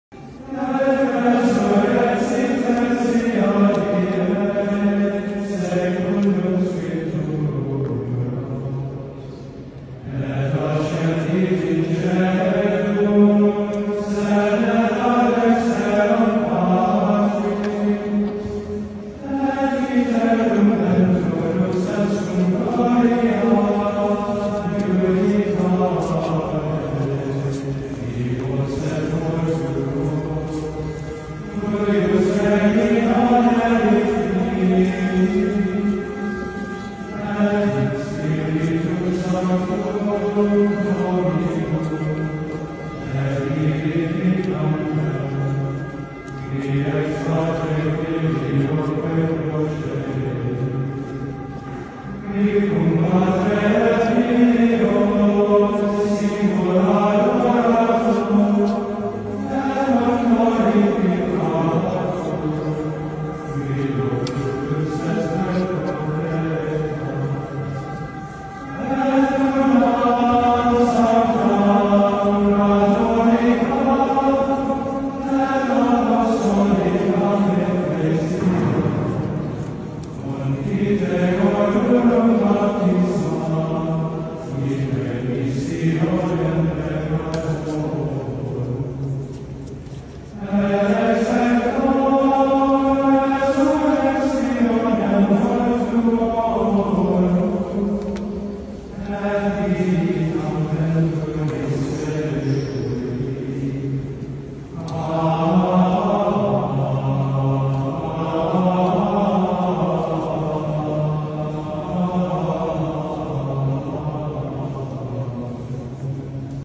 ATIN GREGORIAN CHANT is universal.
It is a recent viral social media post of a group of pilgrims singing Credo IV.
Mp3 Download • Live Rec.
In this particular recording, the singers were a group of US seminarians from Virginia on pilgrimage to Rome. Yet, when they chant in Latin, they ceased to sound American. They didn’t sound Italian, French, or African either.
Their accents disappeared.